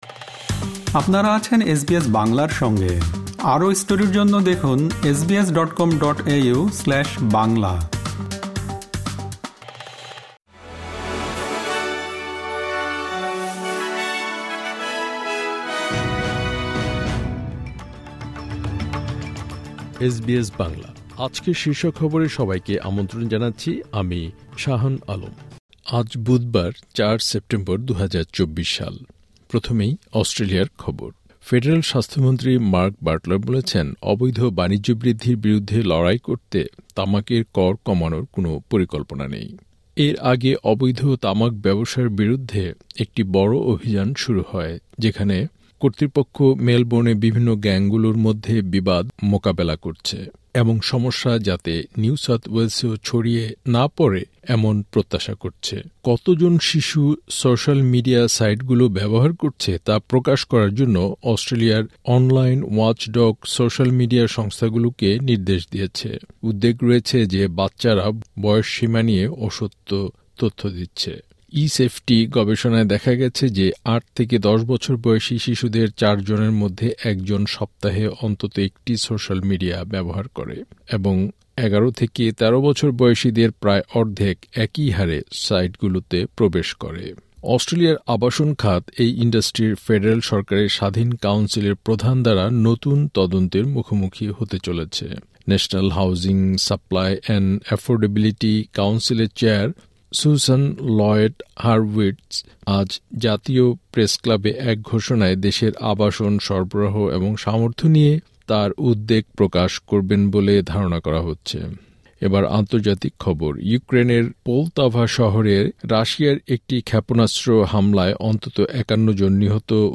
এসবিএস বাংলা শীর্ষ খবর: ৪ সেপ্টেম্বর, ২০২৪